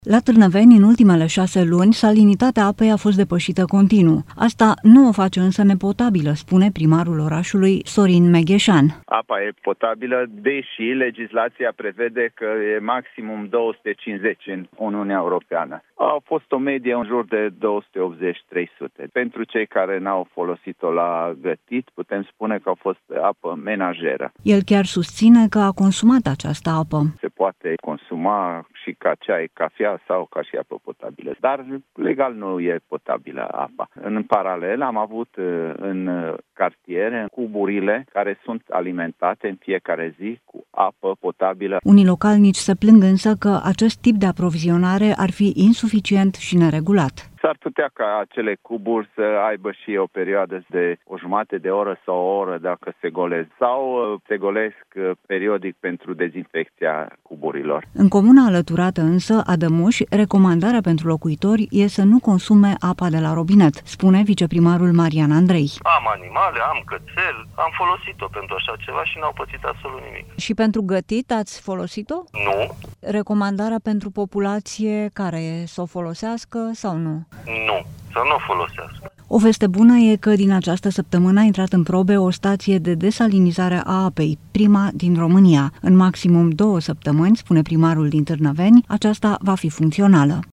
Asta nu o face, însă, nepotabilă, spune primarul orașului, Sorin Megheșan.
În comuna alăturată, Adămuș, recomandarea pentru locuitori este să nu consume apa de la robinet, spune viceprimarul Marian Andrei.